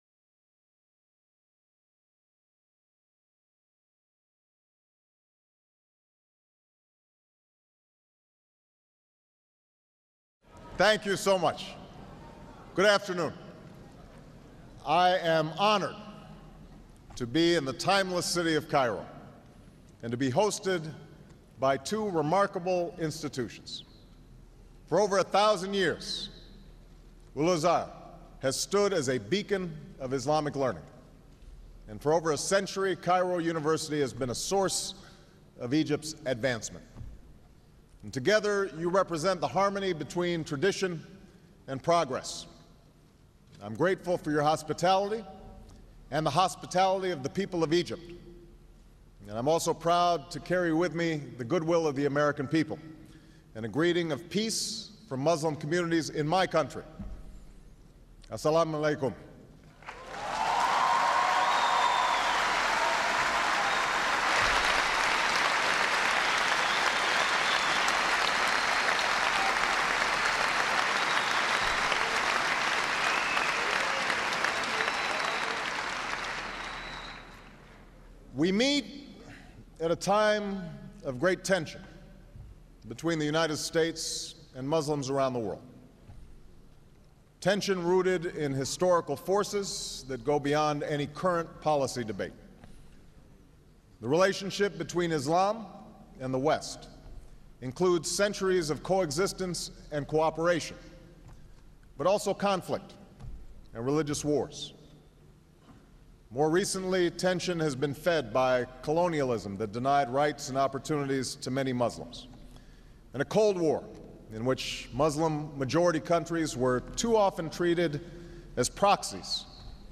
June 4, 2009: Address at Cairo University